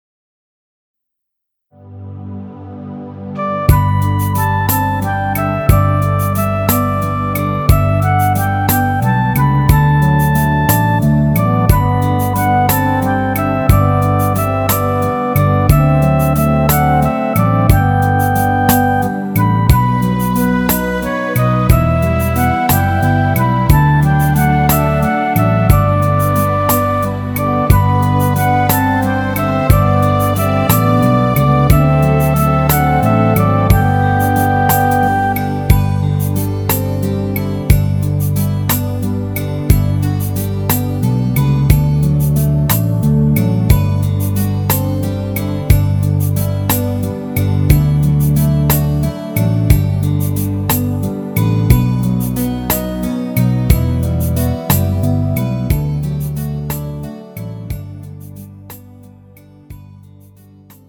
음정 G 키
장르 가요 구분 Pro MR